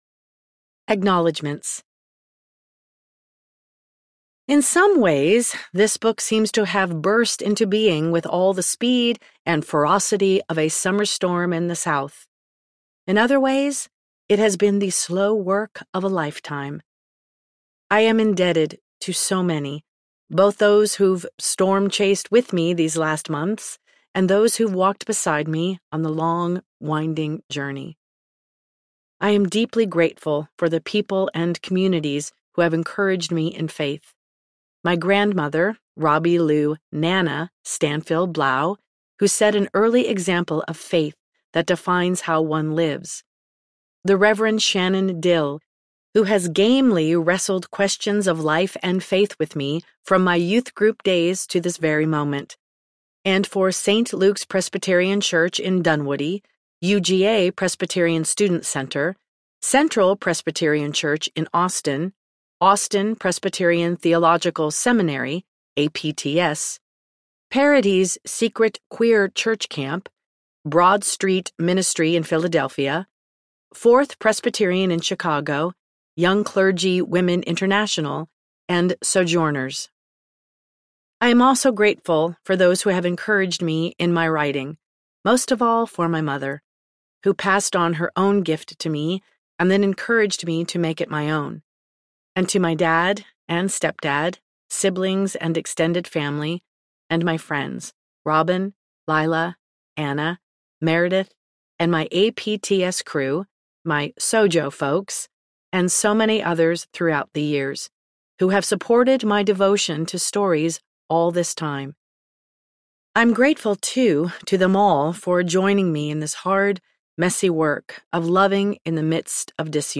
Holy Disunity Audiobook
Narrator
9.5 Hrs. – Unabridged